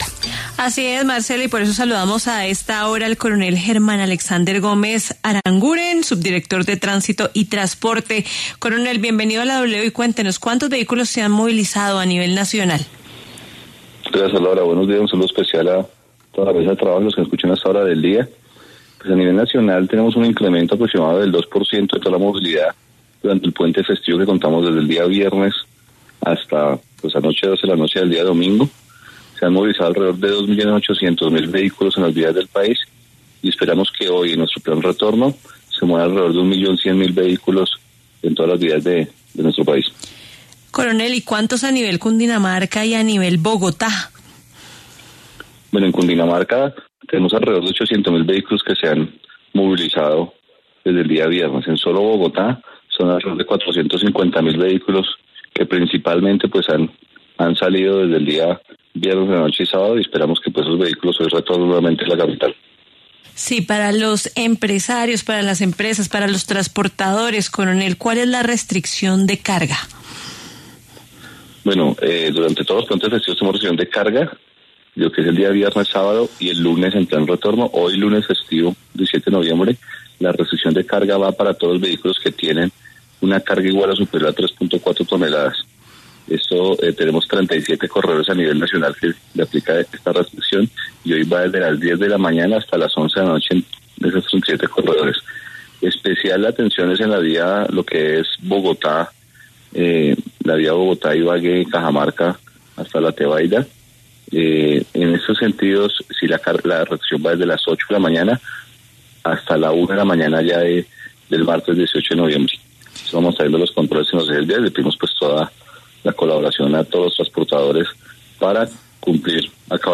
El coronel Germán Alexander Gómez Aranguren, subdirector de Tránsito y Transporte, pasó por los micrófonos de La W y entregó un balance de movilidad de este fin de semana con fuente festivo.